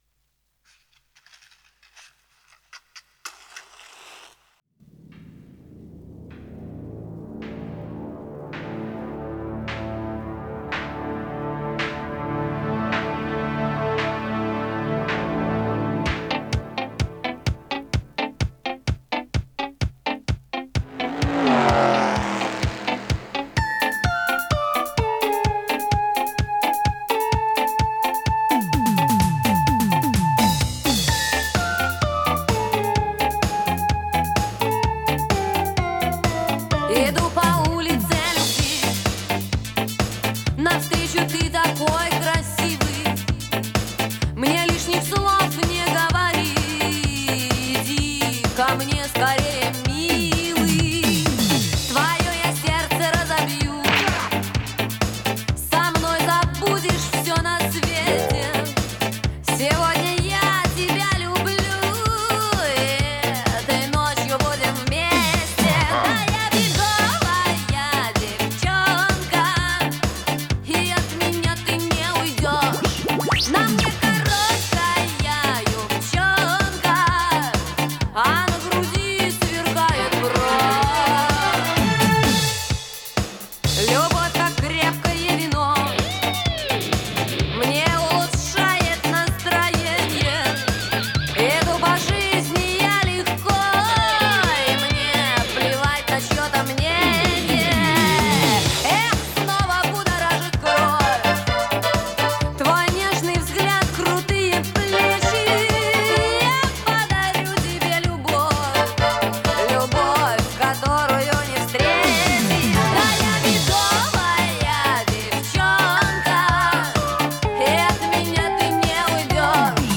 Жанр: Pop, Schlager